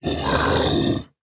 evil-rabbit-v3.ogg